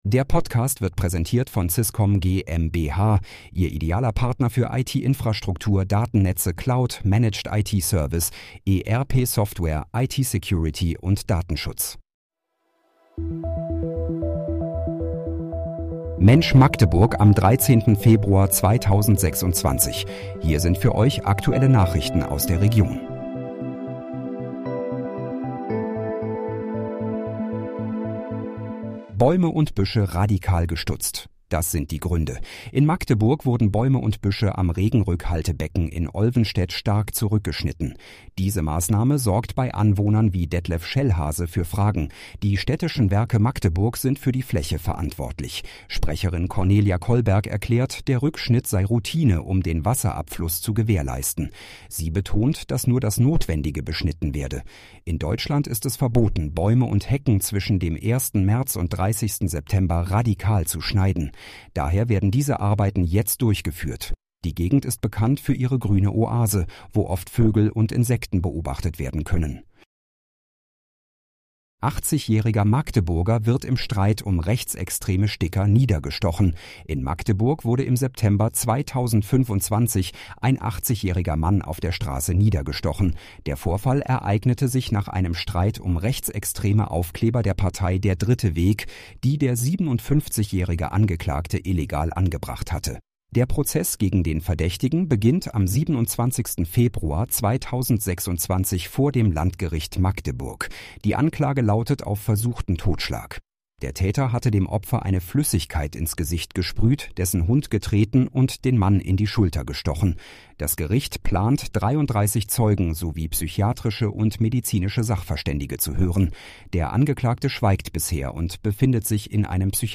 Mensch, Magdeburg: Aktuelle Nachrichten vom 13.02.2026, erstellt mit KI-Unterstützung